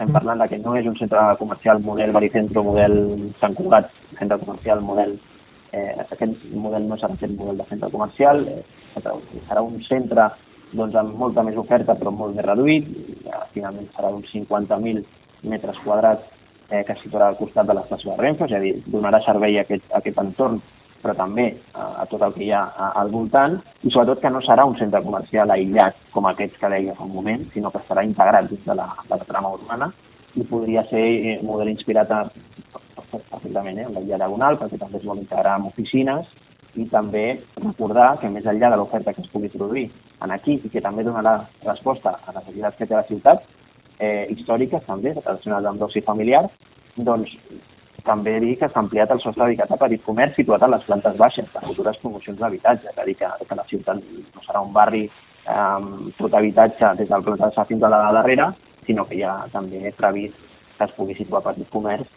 Declaracions de Carlos Cordon